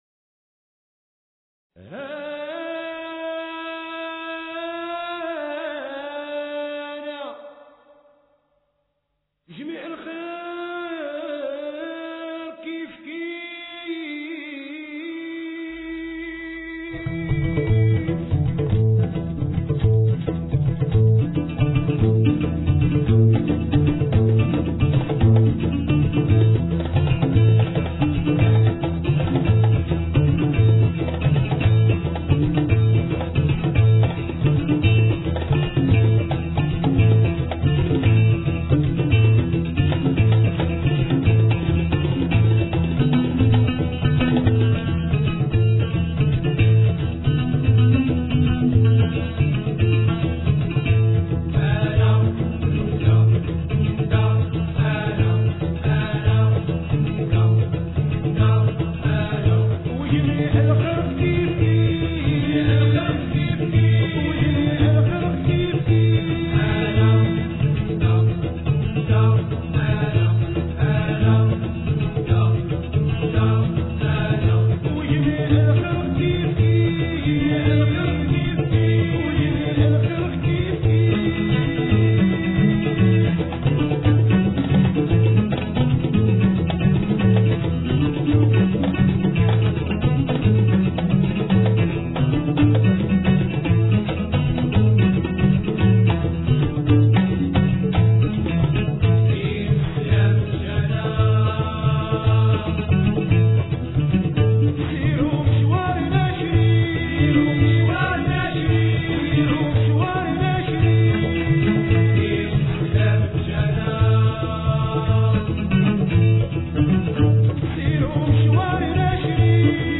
Trance Music of Morocco